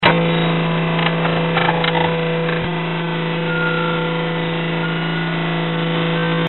elevator.mp3